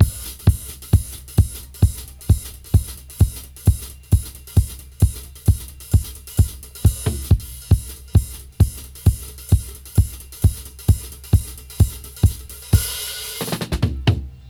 134-DRY-01.wav